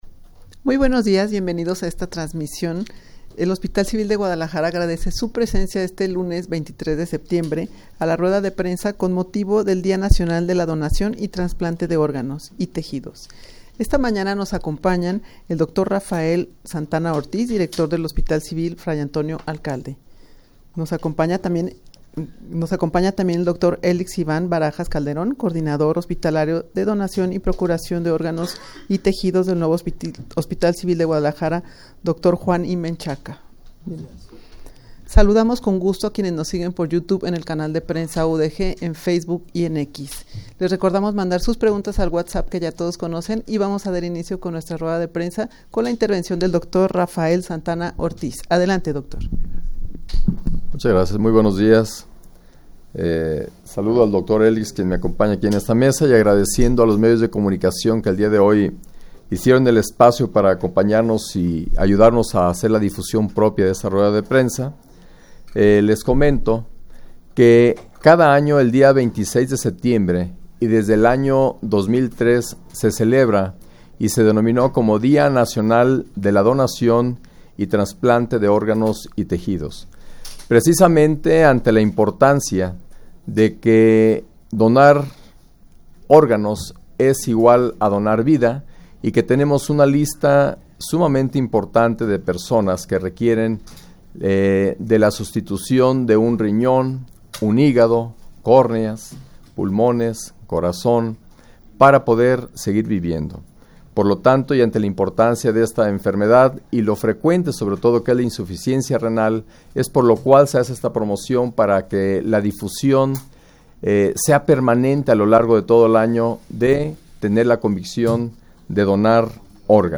Audio de la Rueda de Prensa
rueda-de-prensa-con-motivo-del-dia-nacional-de-la-donacion-y-trasplante-de-organos-y-tejidos.mp3